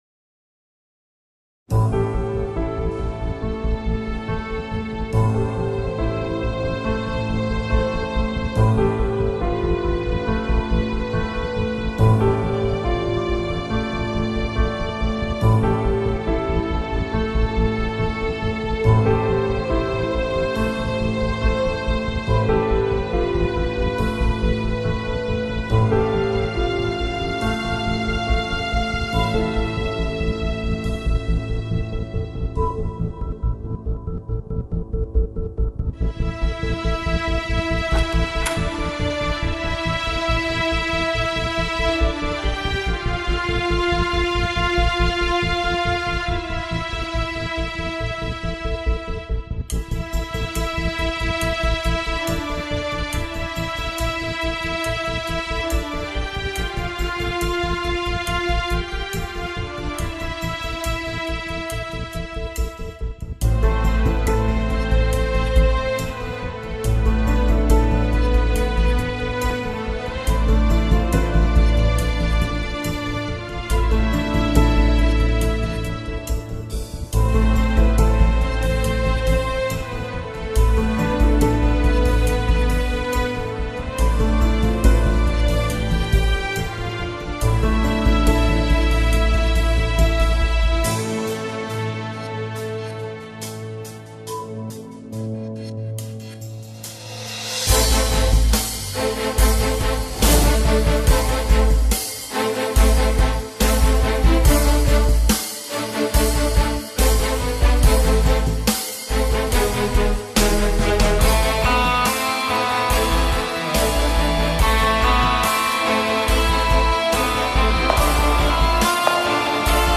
tema dizi müziği, duygusal heyecan gerilim fon müziği.